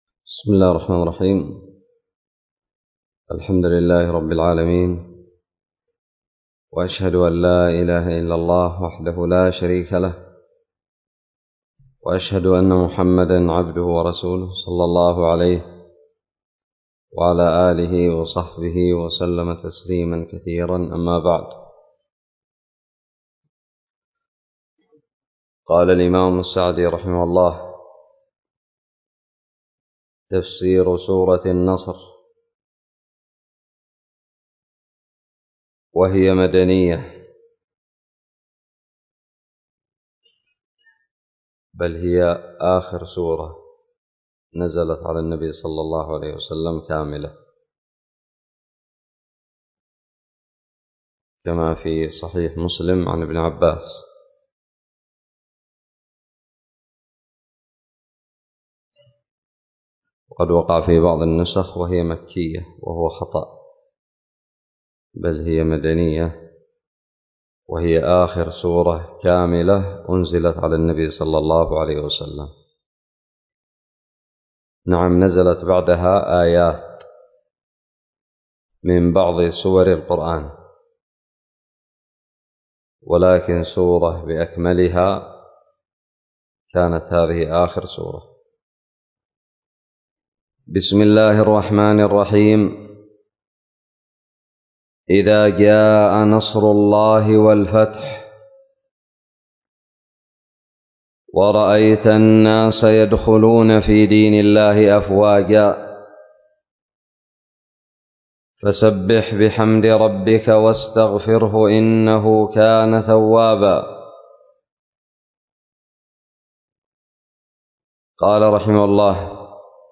ألقيت بدار الحديث السلفية للعلوم الشرعية بالضالع